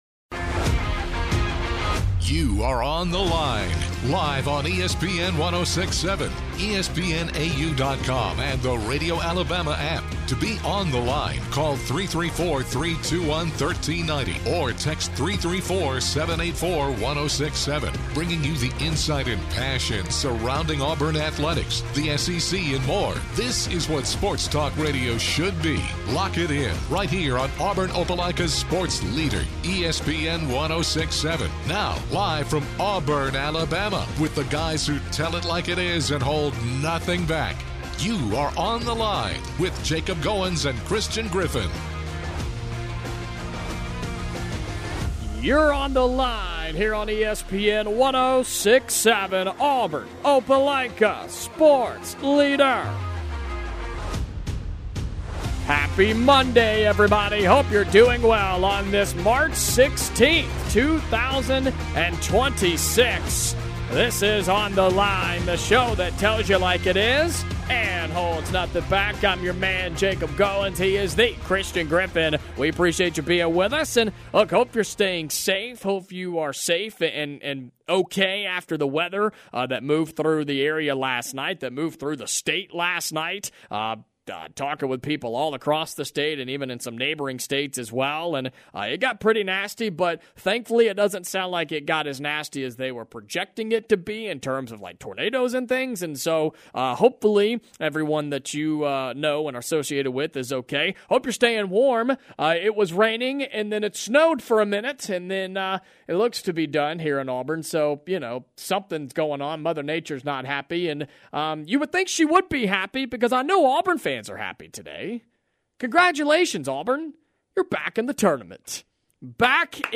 The guys react to Auburn accepting NIT tournament invitation and the pros and cons of the decision.